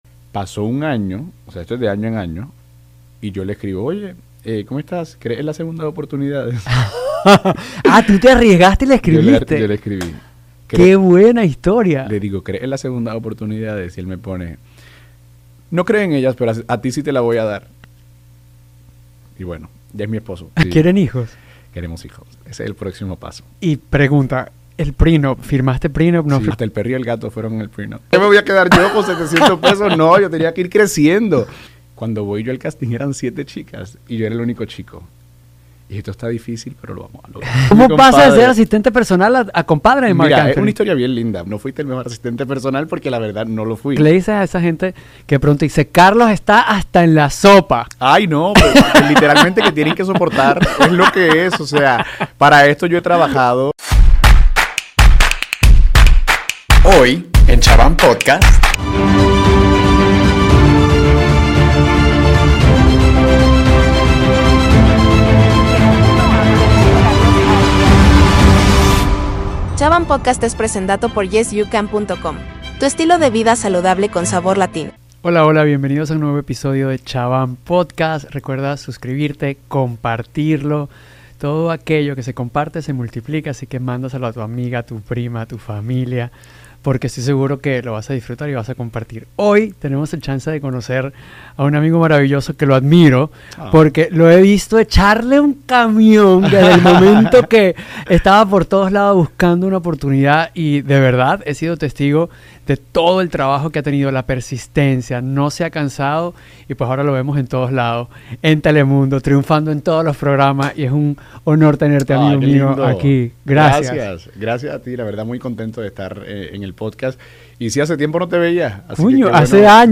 Esta semana, Alejandro Chabán nos trae una conversación fascinante con el destacado periodista y presentador puertorriqueño, Carlos Adyan.